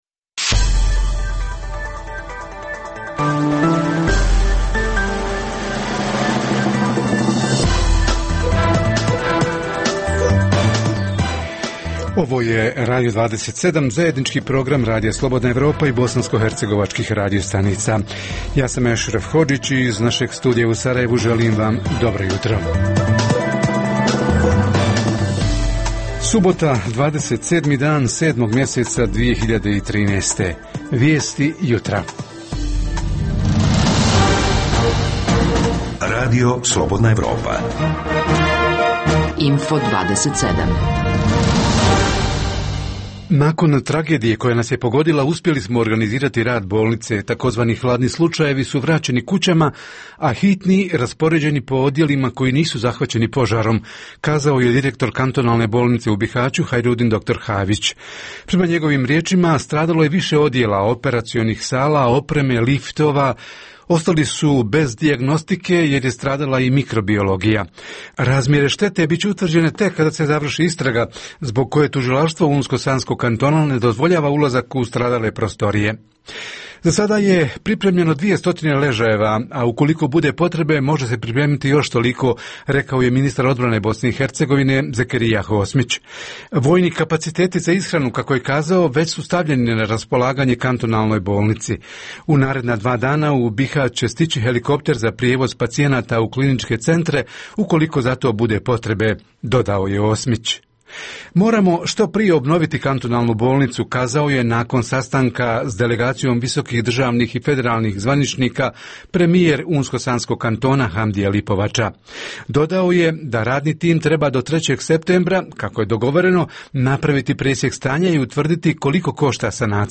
Radio 27 jutros donosi: - Info plus: gdje su i kako zbrinuti pacijenti iz dijelova bihaćke bolnice koji su bili zahvaćeni požarom, ko je i kakvu pomoć ponudio, šta se od ponuđenog već koristi? O tome naš reporter s lica mjesta.